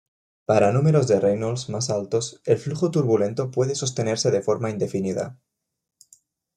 Pronounced as (IPA) /ˈnumeɾos/